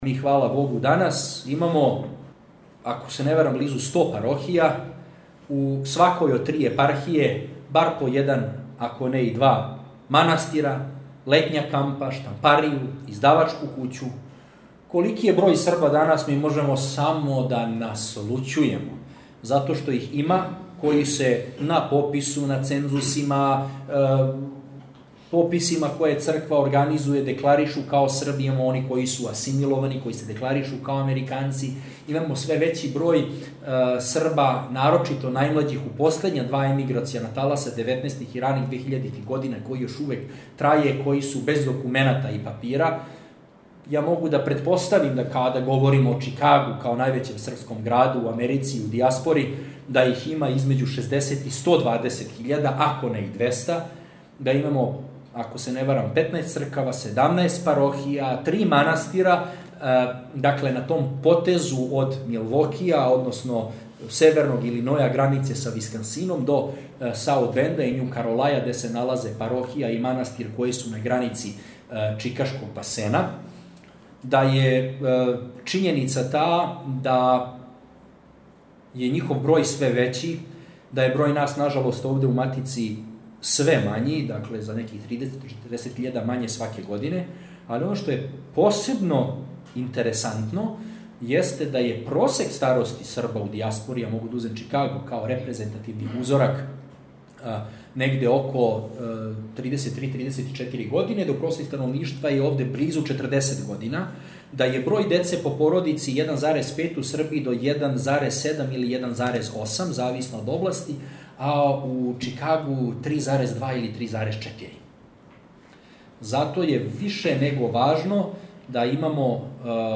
Навечерје празника свих Светих у храму Св. Тројице у Земуну